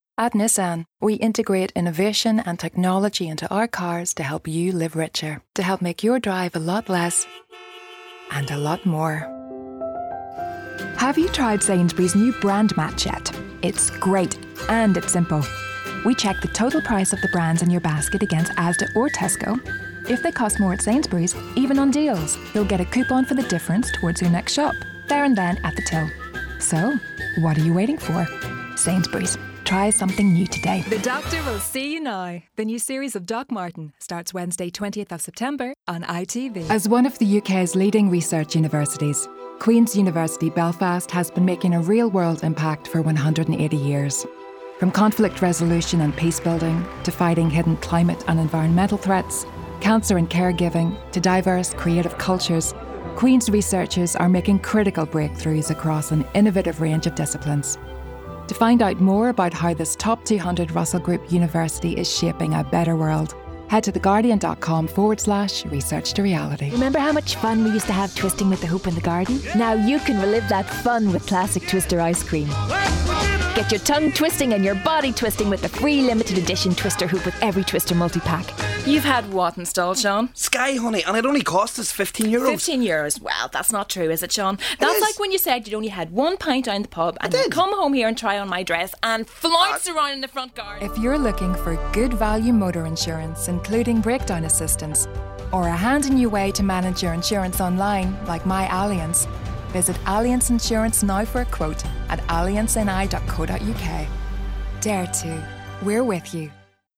Northern Irish